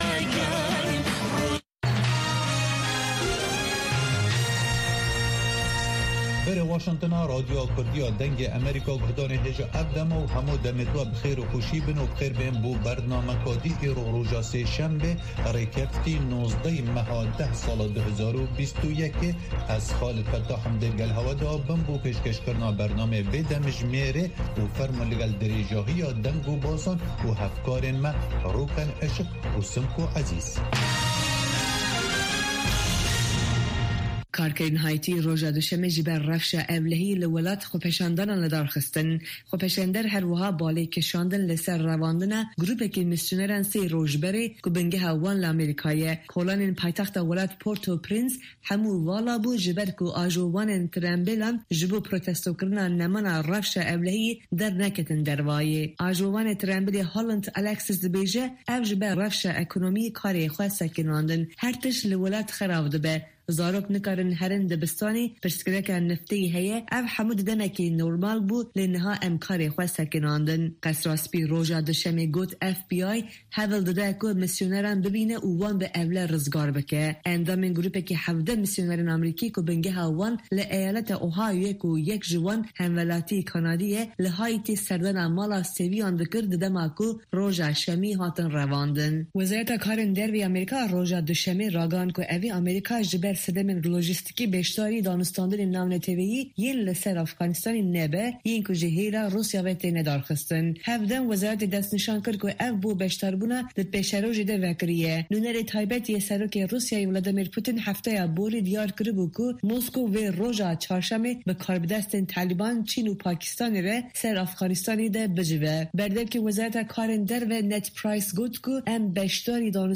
هەواڵەکانی 1 ی پاش نیوەڕۆ